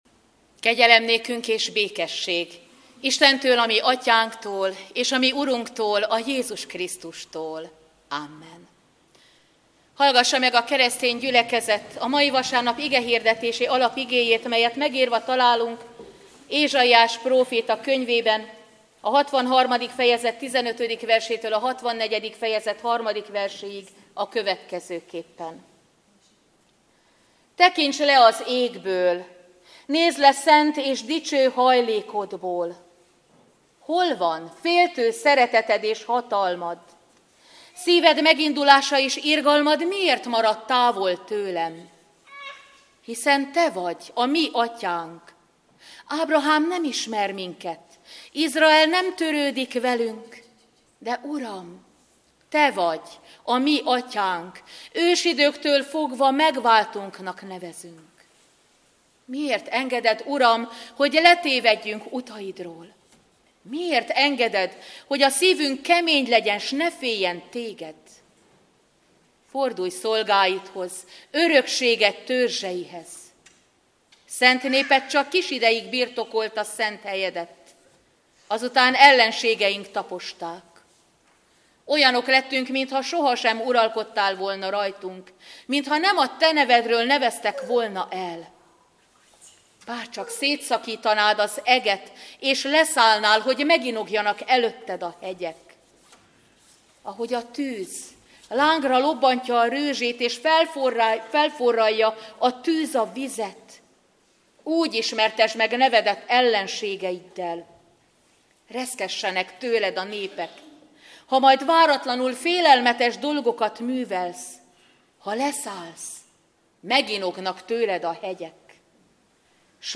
Advent 2. vasárnapja - Egyenesedjetek fel, és emeljétek fel fejeteket, mert közeledik a megváltásotok.